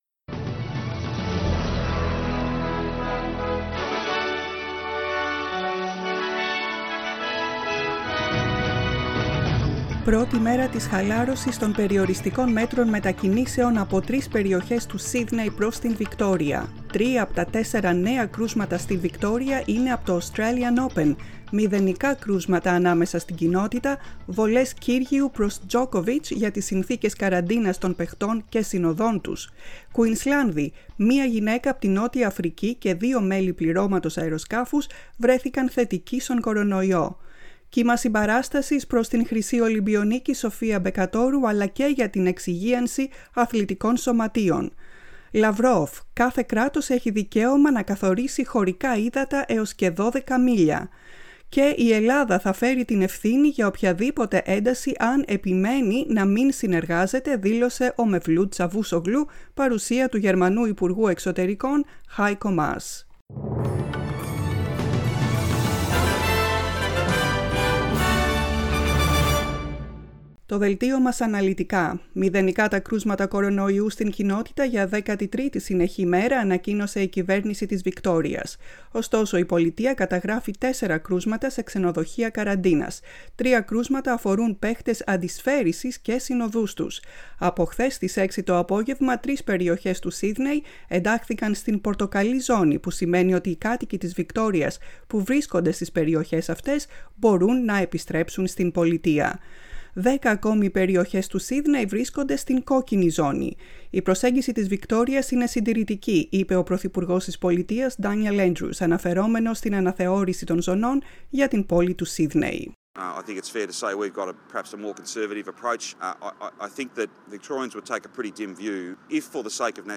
News in Greek, 19.01.2021